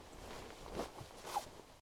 action_open_inventory_1.ogg